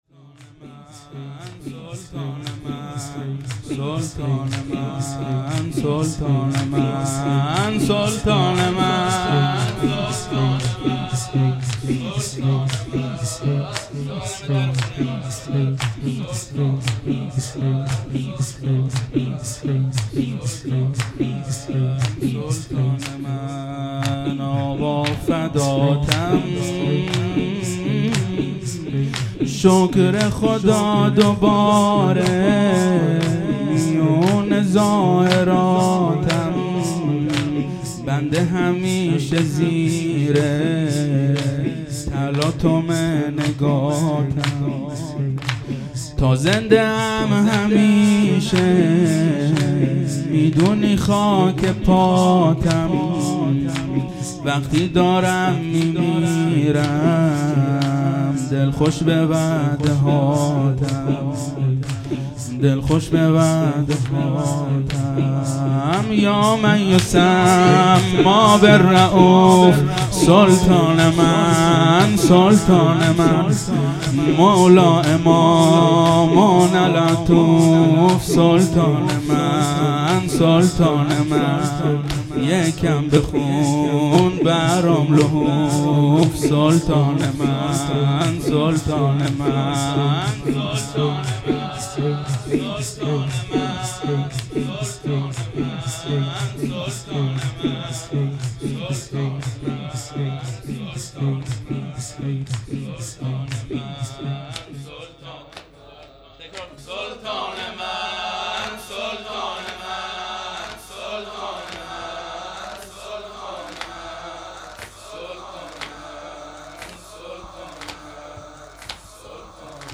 زمینه/ آقا فداتم، شکر خدا دوباره میون زائراتم